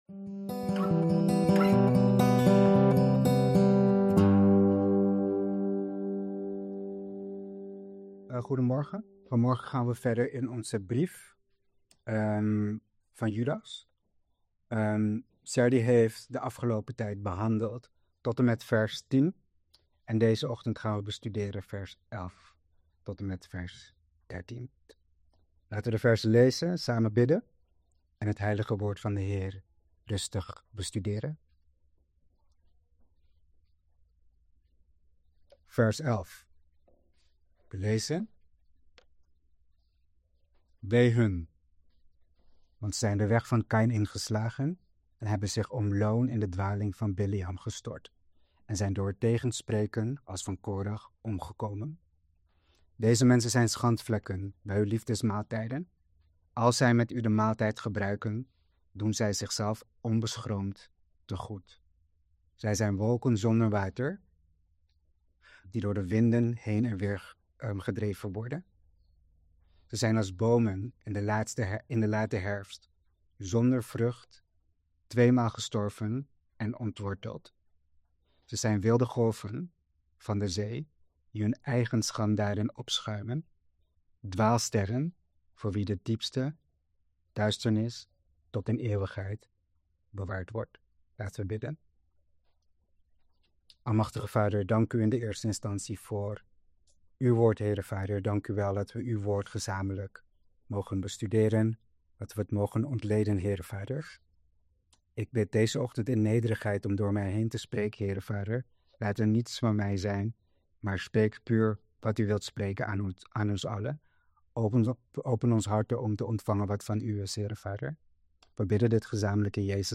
Preek over Judas 1:11-13 | Bijbeluitleg